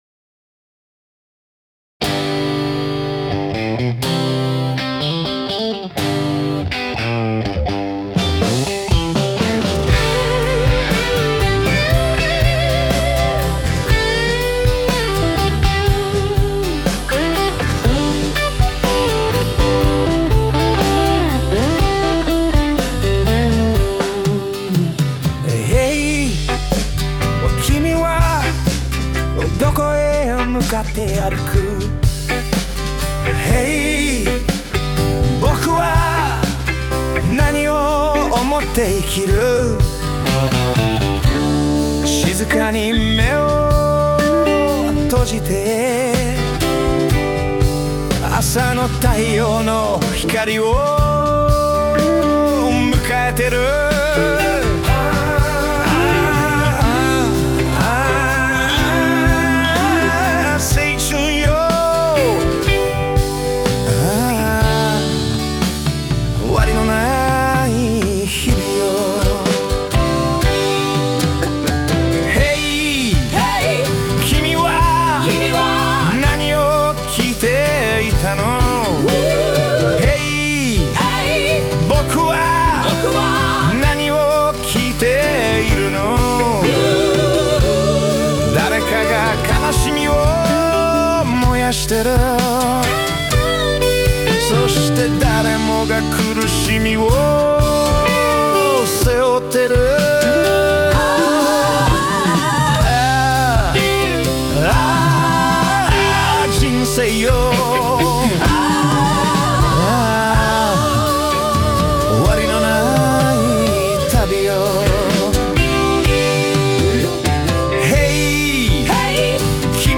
実は14歳の頃から僕はギターを弾き始めておりまして、今でもこうして歌を作ったり、歌ったりしているんです。